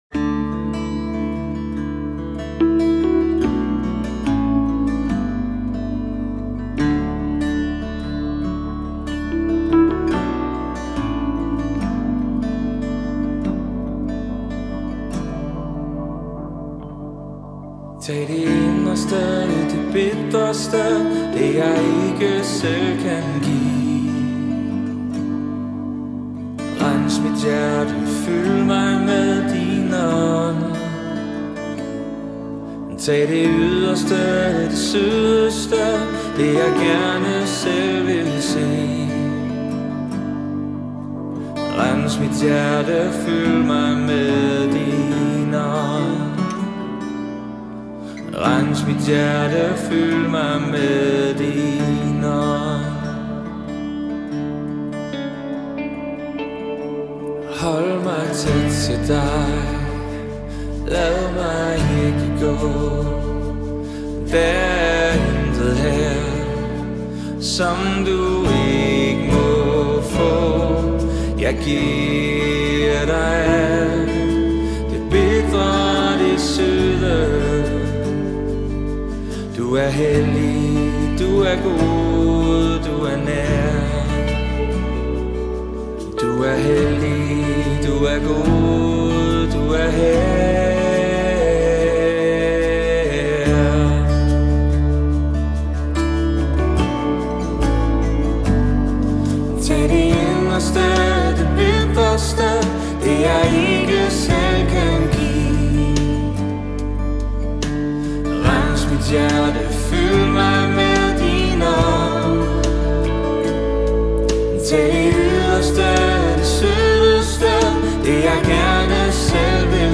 35 просмотров 12 прослушиваний 2 скачивания BPM: 70